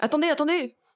VO_ALL_Interjection_03.ogg